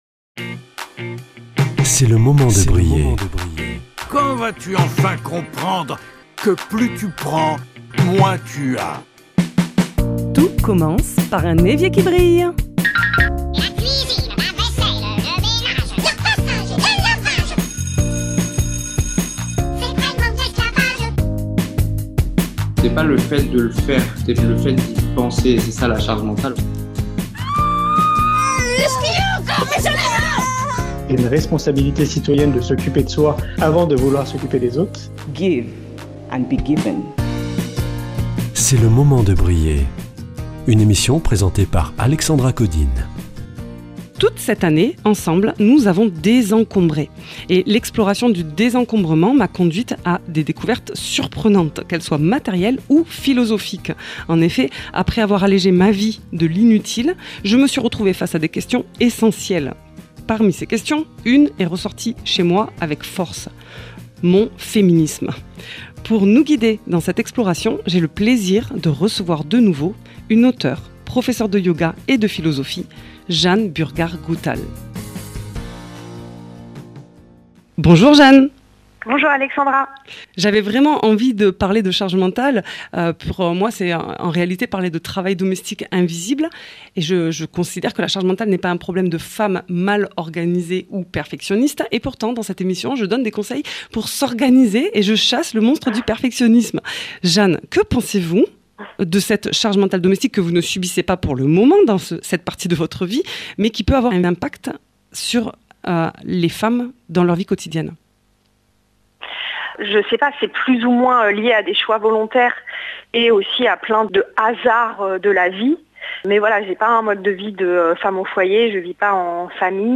Nous parlons avec notre invitée de féminisme y compris au sein de leur foyer